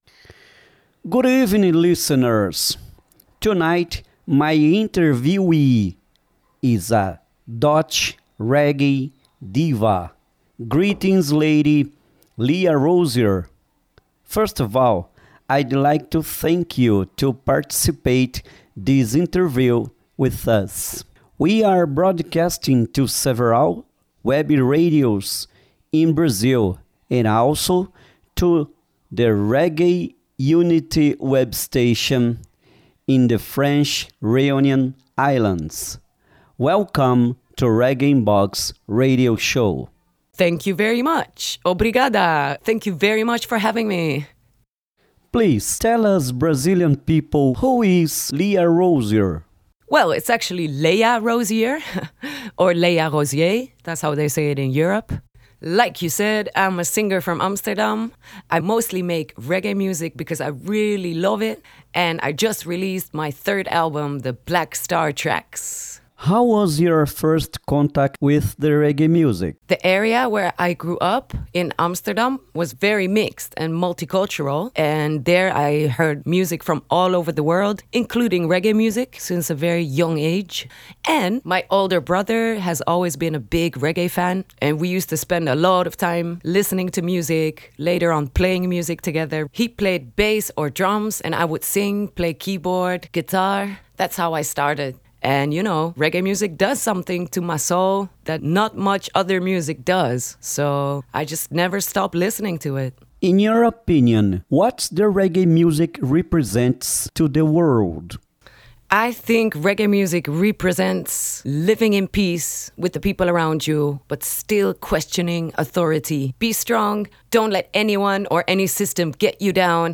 RIB Entrevista